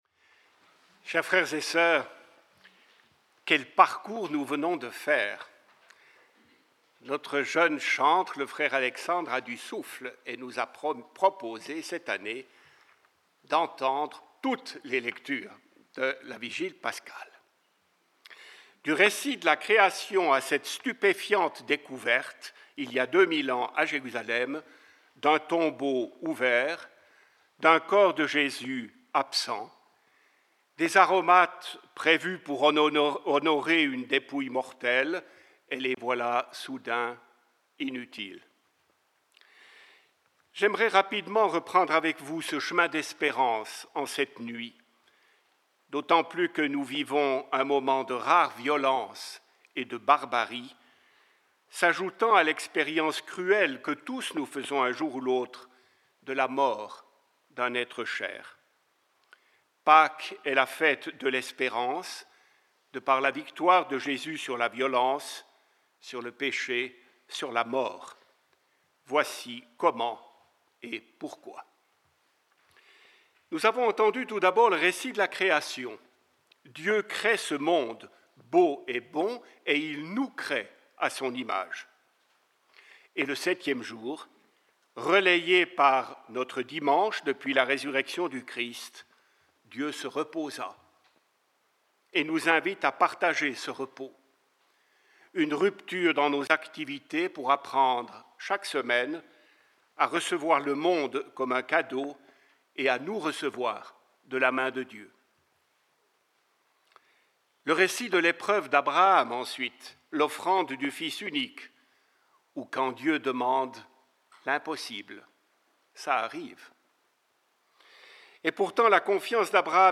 Hier soir, les frères se sont réunis avec de nombreux fidèles dans la chapelle du couvent de St-Hyacinthe pour célébrer la veillée pascale.